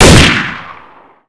m4a1_unsil-1.wav